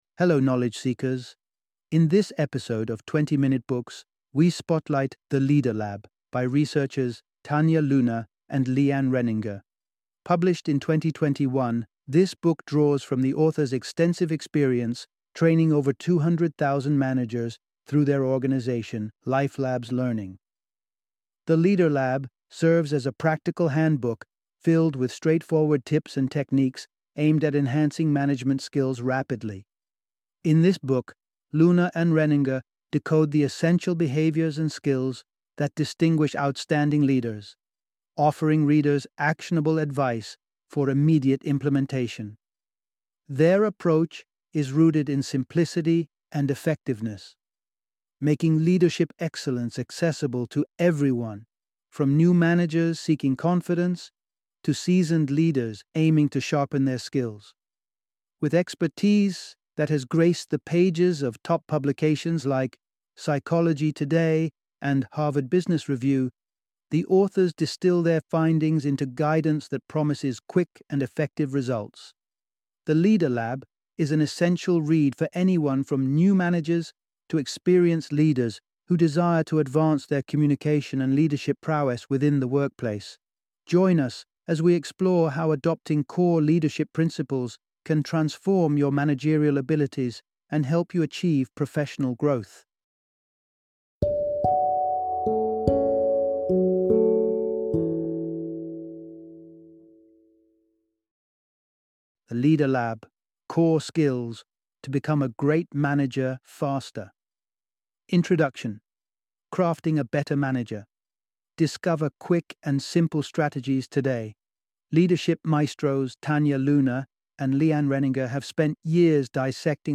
The Leader Lab - Audiobook Summary